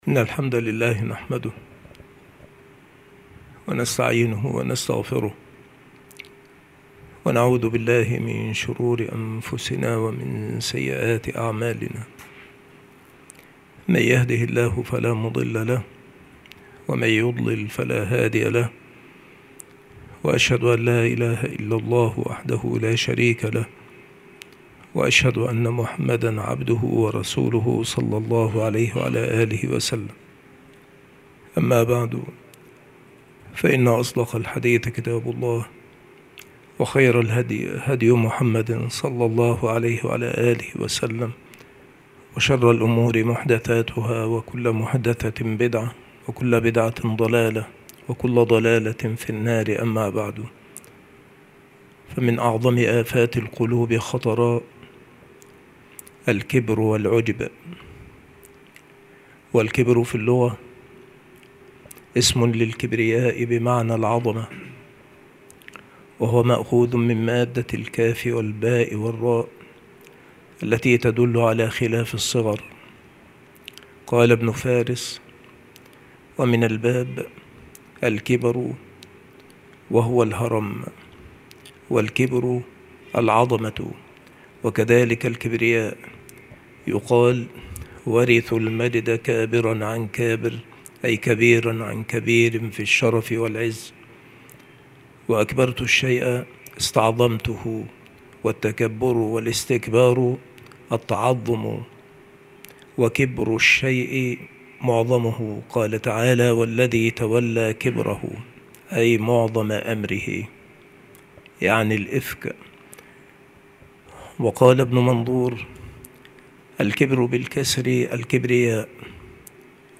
مكان إلقاء هذه المحاضرة المكتبة - سبك الأحد - أشمون - محافظة المنوفية - مصر عناصر المحاضرة : الكبر في اللغة. الكبر في الاصطلاح. صفة المتكبر. أسباب الكبر. أنواع الكبر.